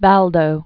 (văldō, väl-), Peter